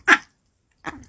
dog
bark_27038.wav